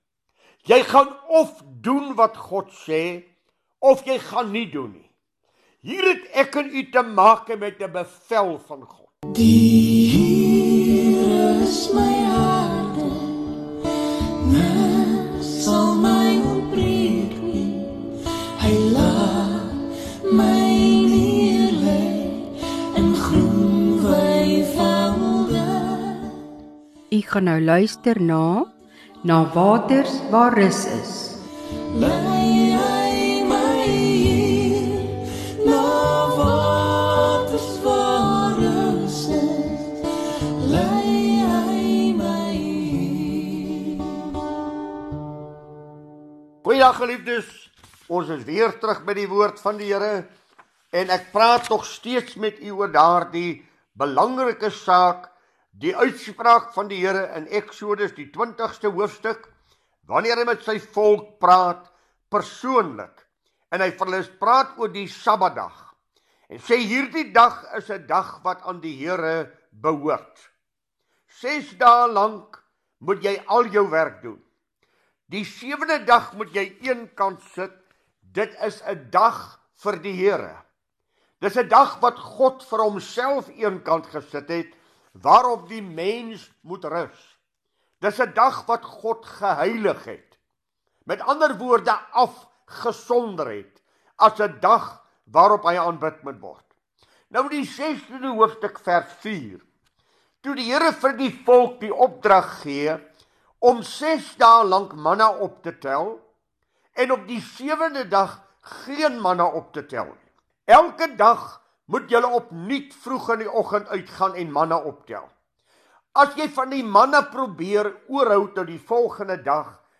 DIE PREDIKER BEHANDEL STEETS DIE GEBRUIK EN DOEL VAN DIE SABBAT EN DIE NUWE TESTAMENTIESE GEBRUIK DAARVAN.